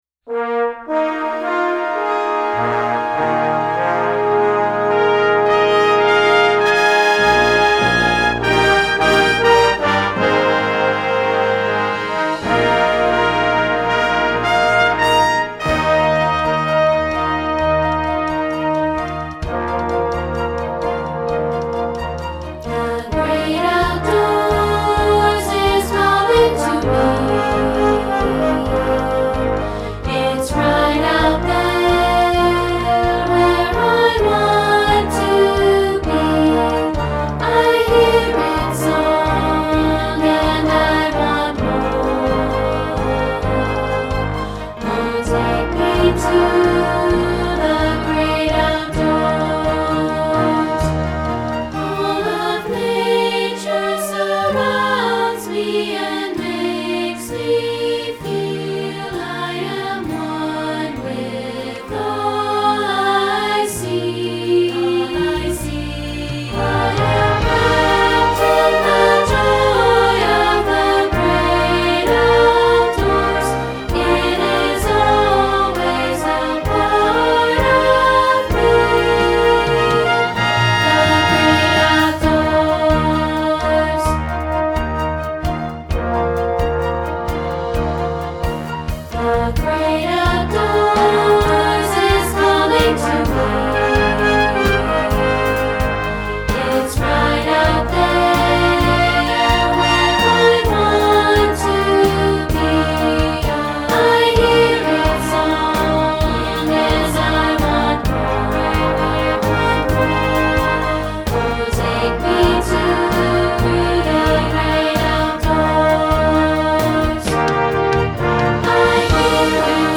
This is a full performance track without the solos.